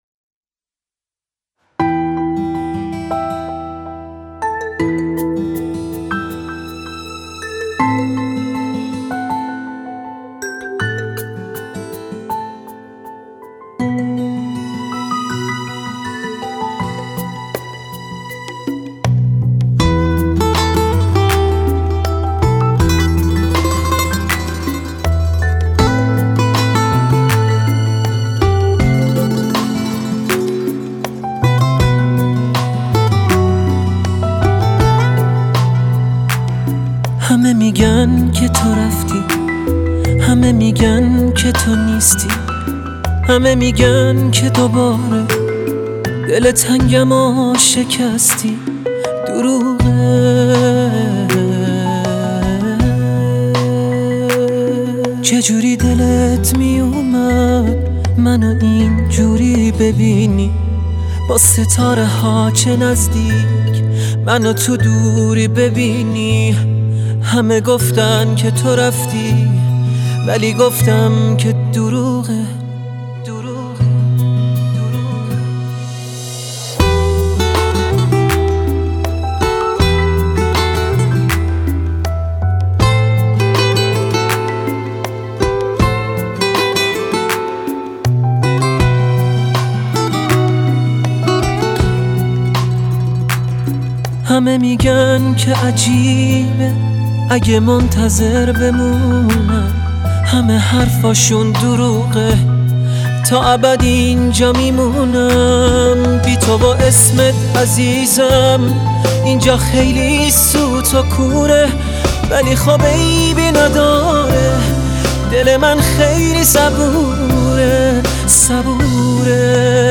آهنگ احساسی آهنگ غمگین